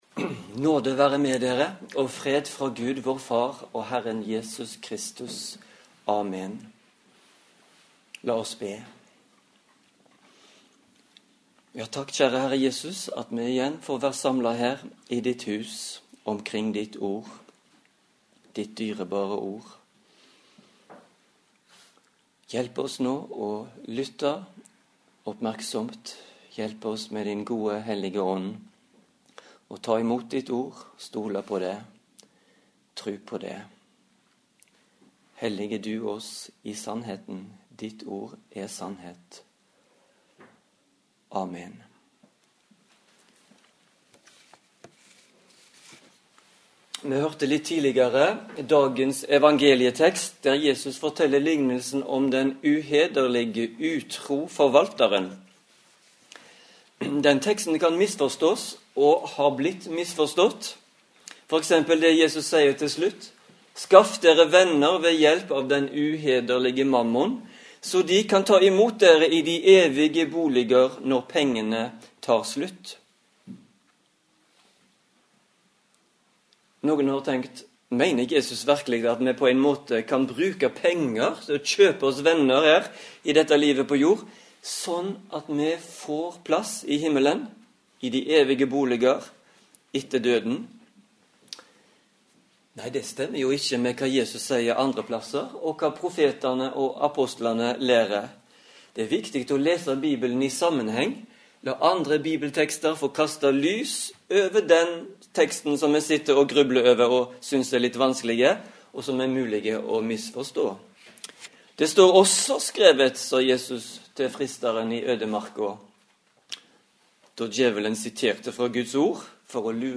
Preken på 9. søndag etter Treenighetsdagen